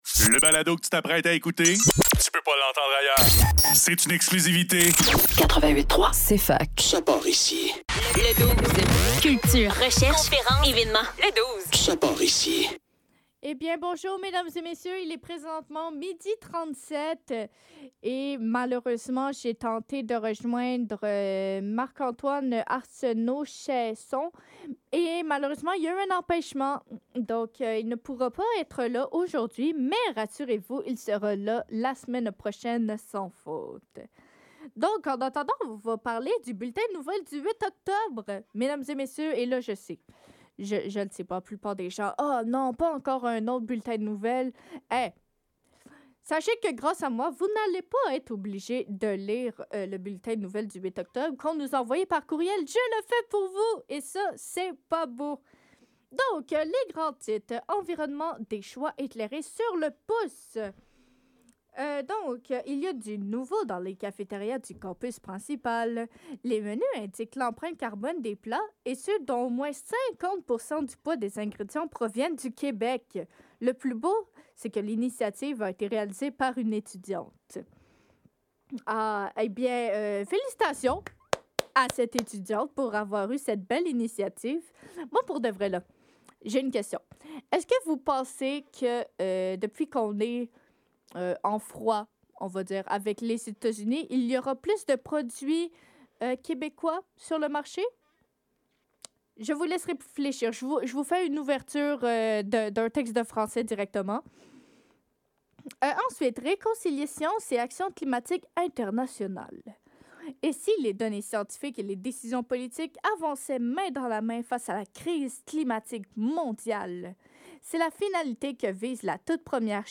Le DOUZE - Bulletin de nouvelles du 8 octobre - 09 Octobre 2025